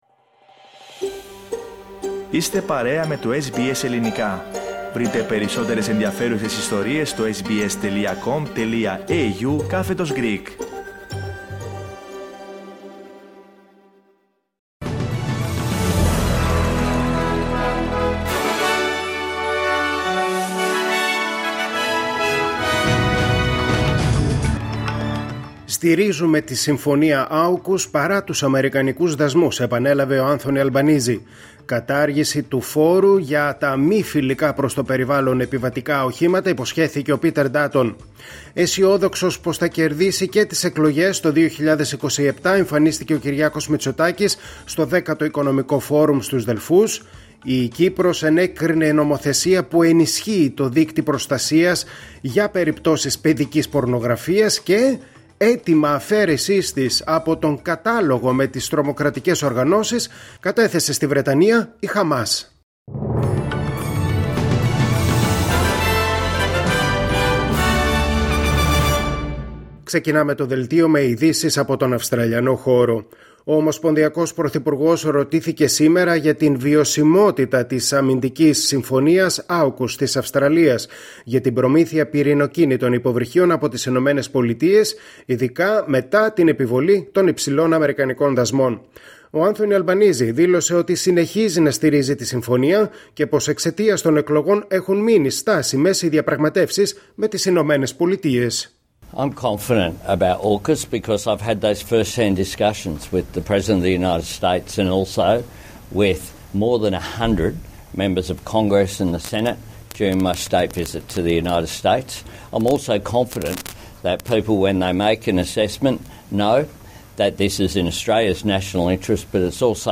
Δελτίο Ειδήσεων Παρασκευή 11 Απριλίου 2025